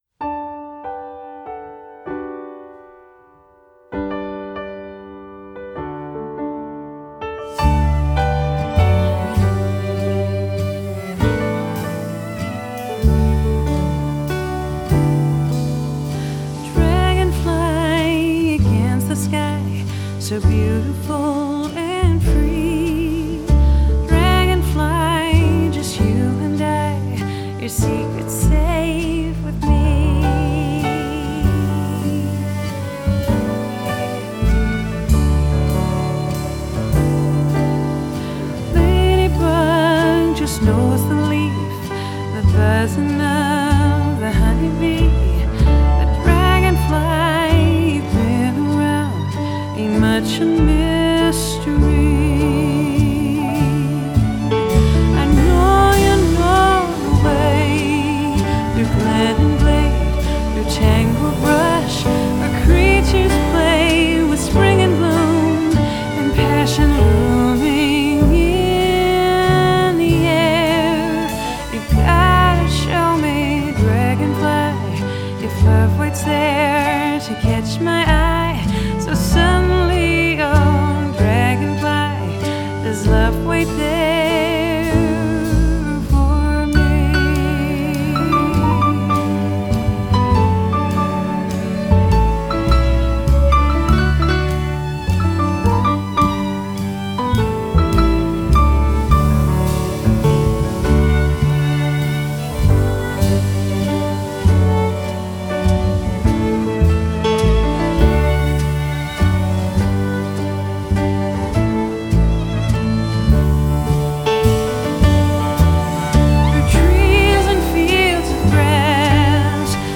Genre: Vocal Jazz